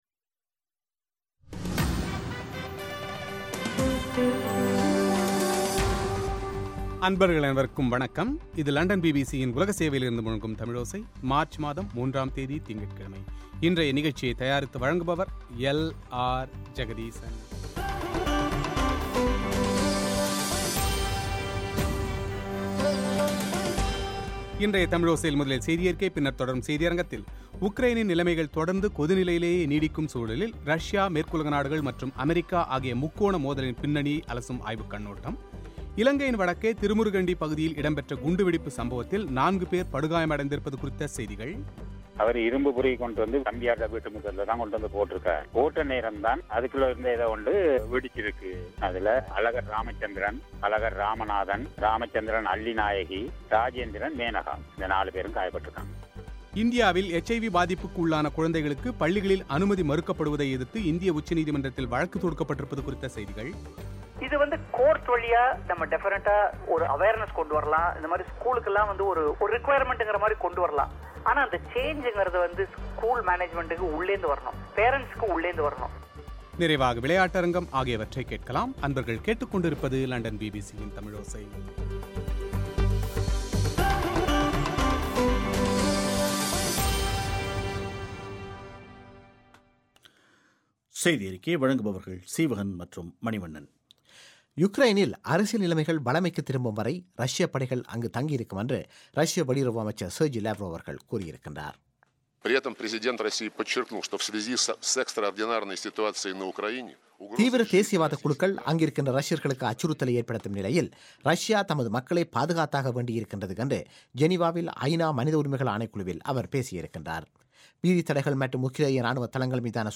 எச் ஐ வி தொற்றுக்குள்ளான ஒரு குழந்தையின் தாயார் ஒருவரிடம் அவரது அனுபவம் குறித்த செவ்வி